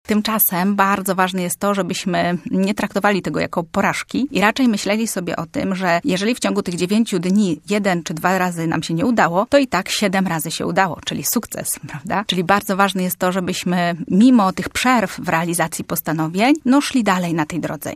Rzucasz cele po 9 dniach? Psycholog: chwila słabości to nie porażka